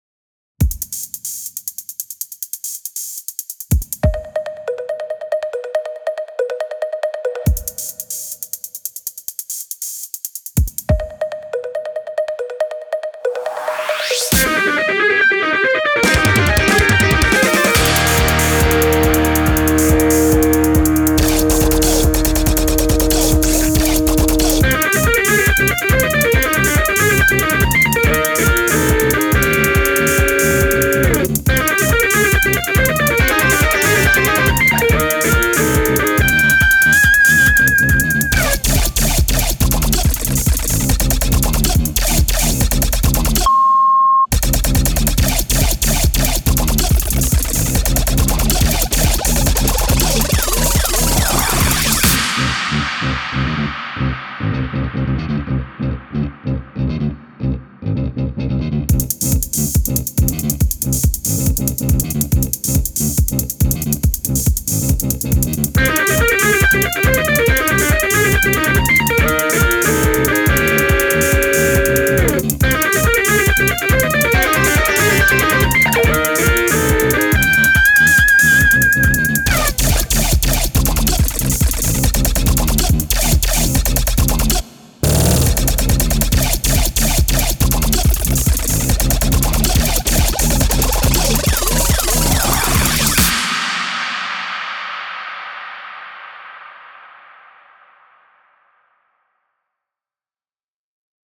ロック
EDM
ギター
シンセ
ベース
打楽器
激しい
テーマ曲